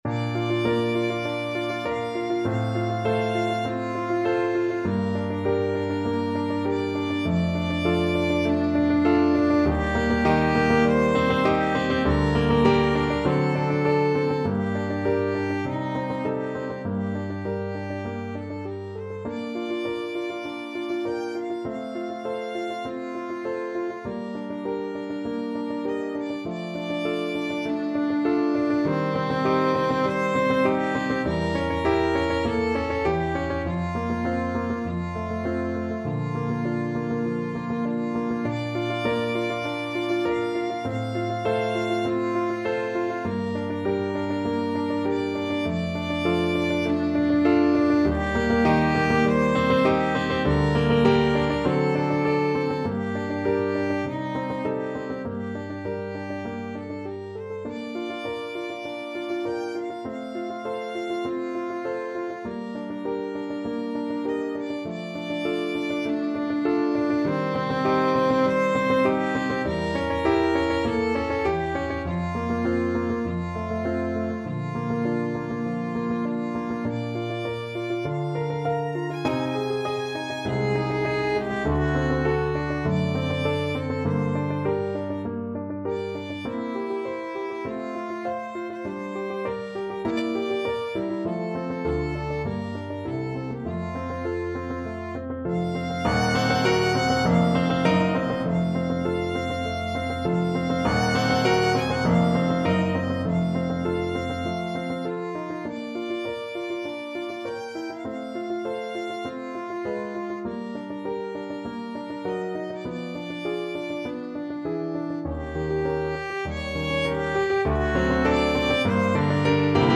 4/4 (View more 4/4 Music)
Allegro moderato (View more music marked Allegro)
Violin  (View more Intermediate Violin Music)
Classical (View more Classical Violin Music)